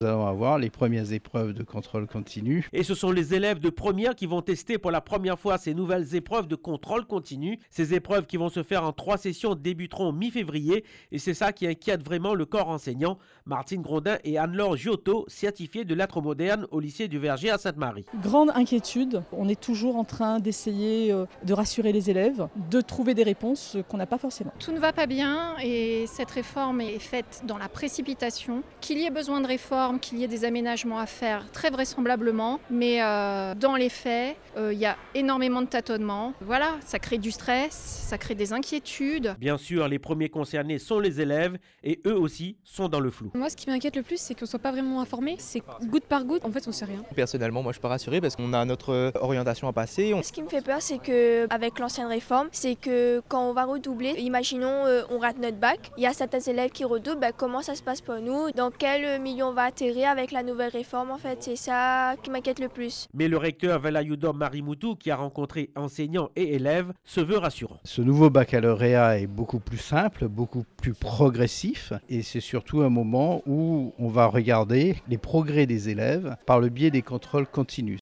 Reportage audio